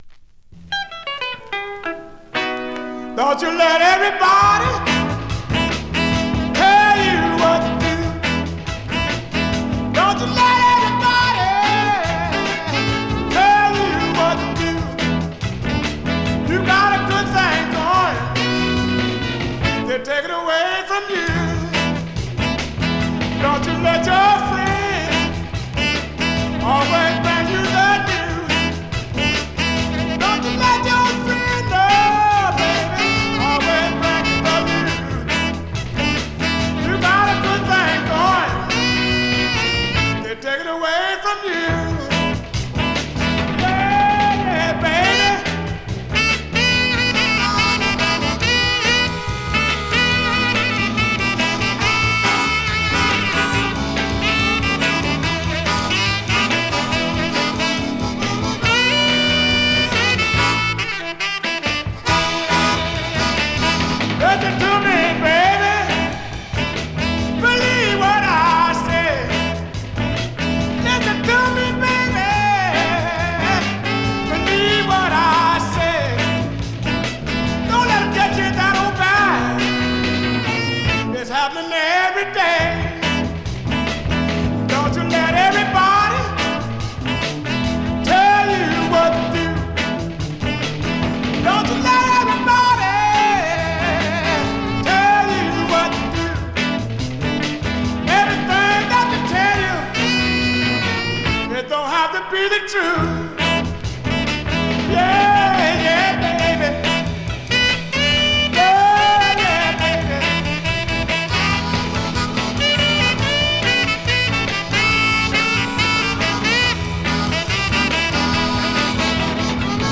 Vinyl has a few light marks plays great .
R&B, MOD, POPCORN , SOUL